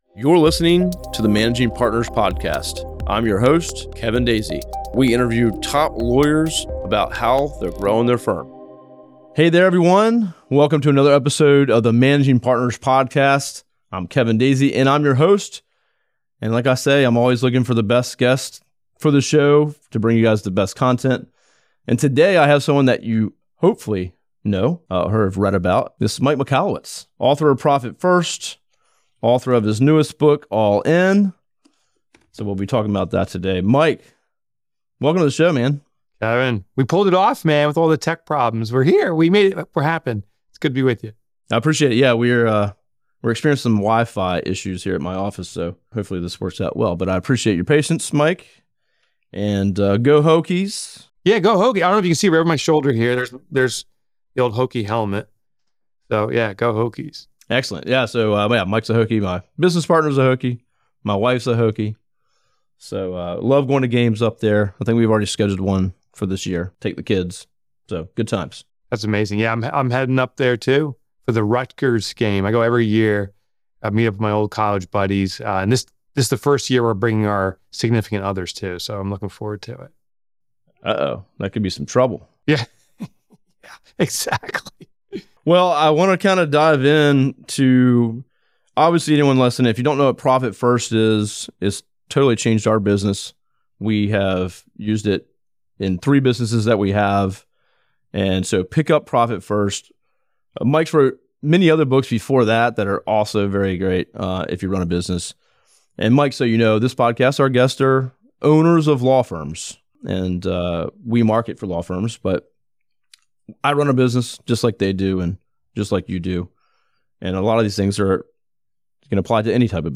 In today’s episode, we sit down with best-selling author, Mike Michalowicz, the mastermind behind books like Profit First and All In.